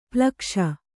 ♪ plakṣa